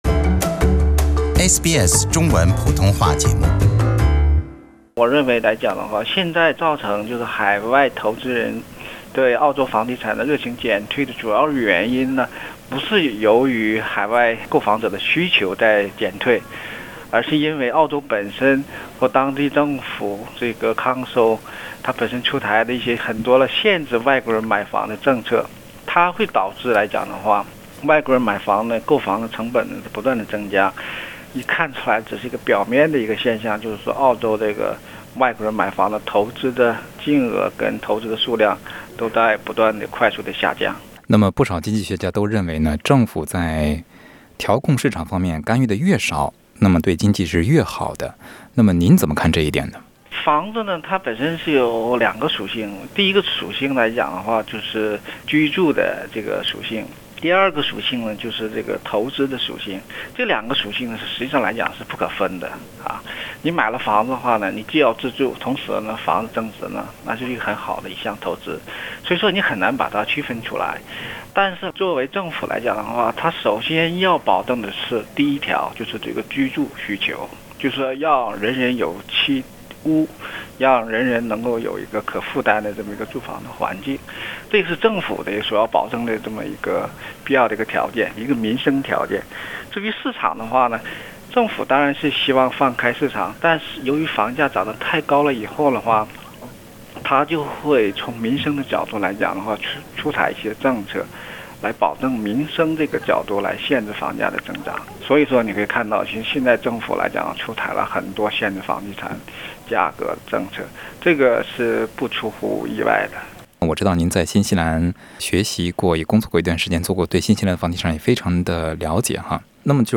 详细的分析请点击收听采访内容